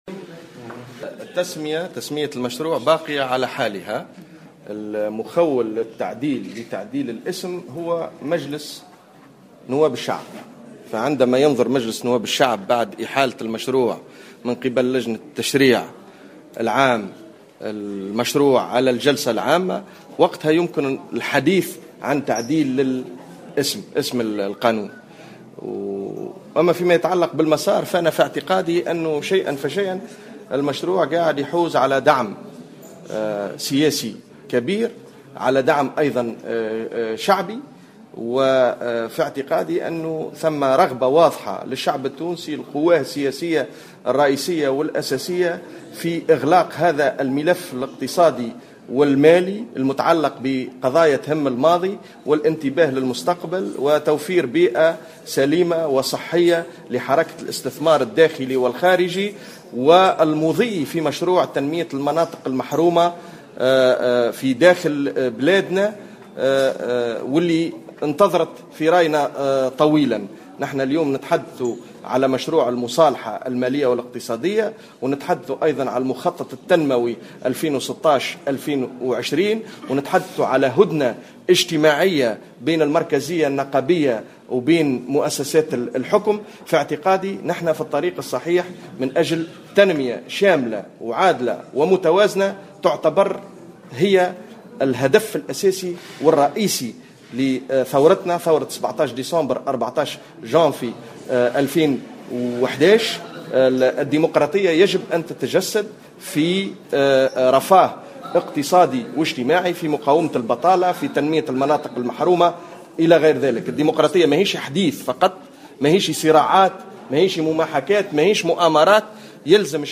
واعتبر شوكات في تصريح لمراسل الجوهرة أف أم على هامش ندوة حوارية نظمها مركز الدراسات الاستراتيجية والدبلوماسية بعنوان" المصالحة الاقتصادية مسار العدالة الانتقالية" بأحد نزل العاصمة اليوم السبت، اعتبر أن هذا القانون يحظى شيئا فشيئا بدعم سياسي وشعبي، وسط رغبة لدى الشعب التونسي لغلق هذا الملف قصد إرساء بيئة سليمة للاستثمار والمضي في مسار تنمية المناطق المحرومة وفق تعبيره.